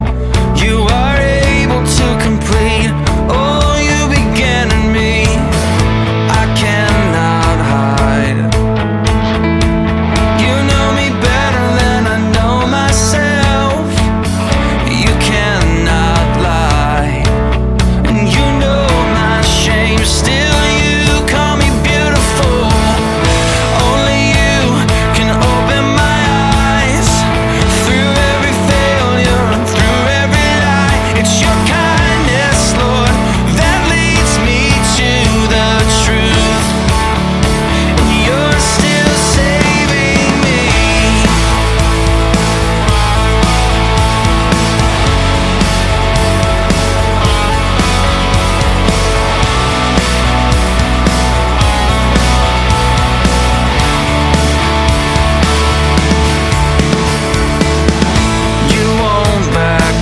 Live-Worship
Kraftvoller und leidenschaftlicher Lobpreis
• Sachgebiet: Praise & Worship